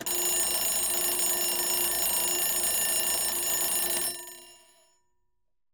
ALARM 1   -S.WAV